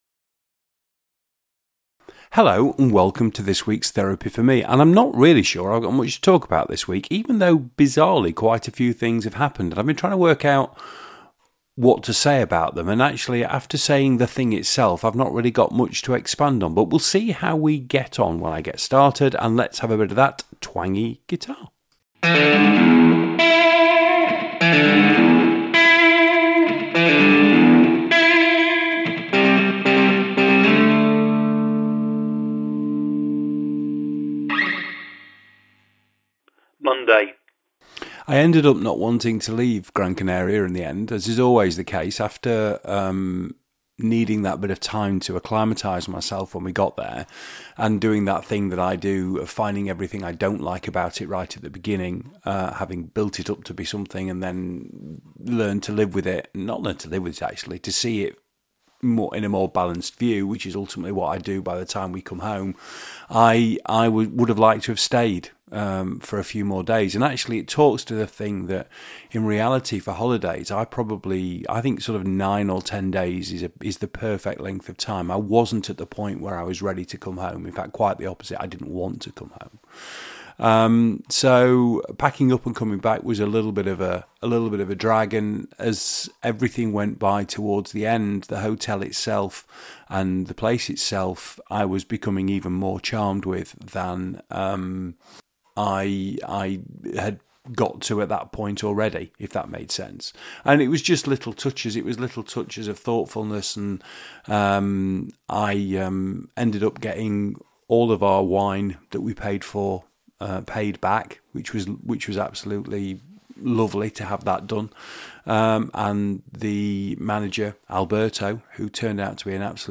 It's remains loose in style, fluid in terms of content and raw - it's a one take, press record and see what happens, affair.